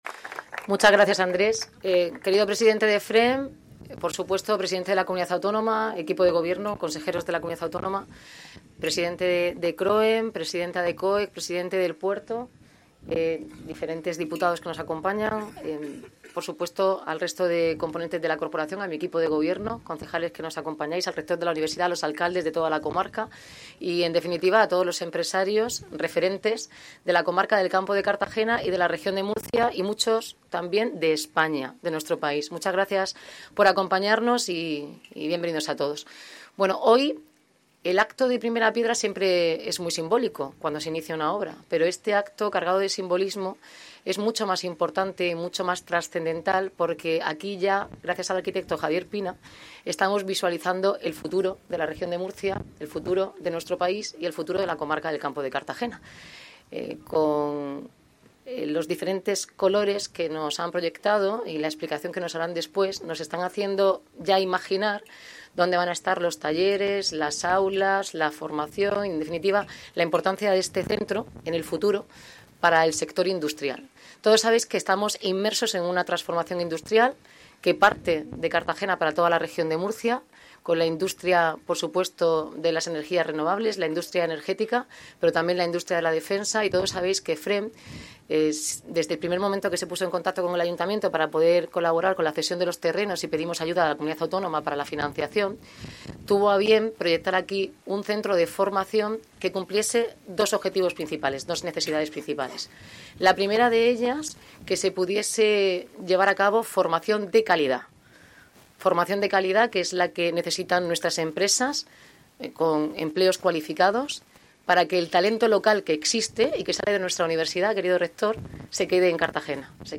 Declraciones de Noelia Arroyo
primera piedra en Los Camachos